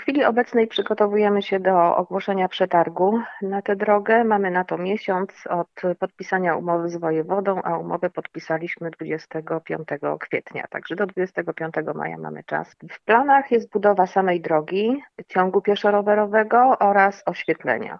– Mówi wójt gminy Julita Pilecka.